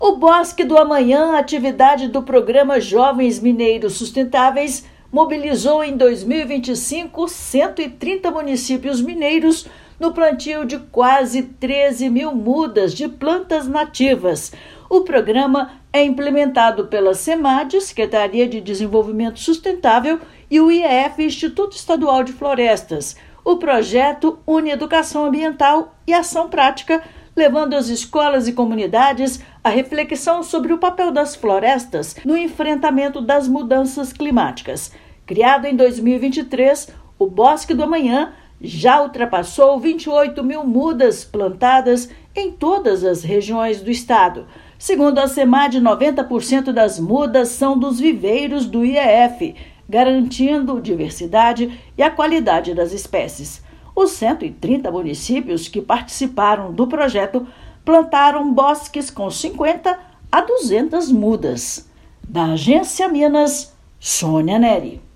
Iniciativa desenvolvida por meio da Semad chega a 130 municípios e fortalece o compromisso do estado com a sustentabilidade. Ouça matéria de rádio.